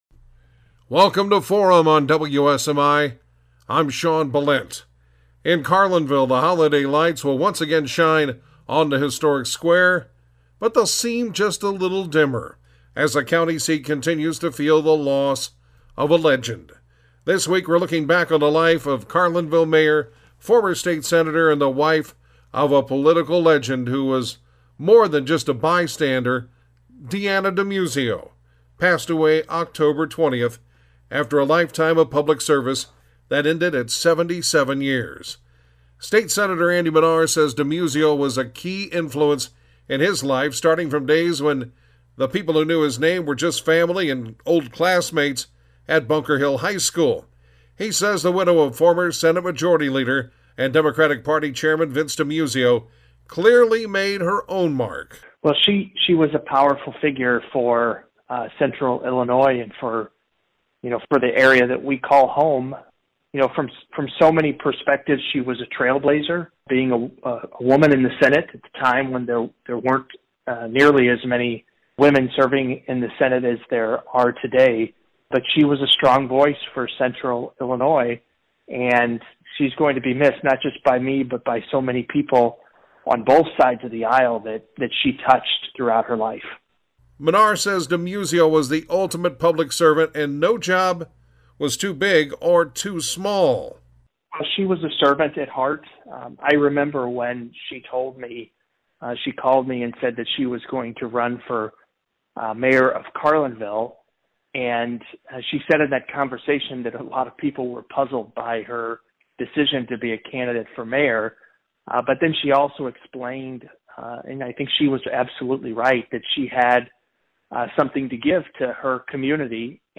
Topic: Remembering Carlinville Mayor and former State Senator Deanna Demuzio Guests: State Senator Andy Manar and former State Senator Frank Watson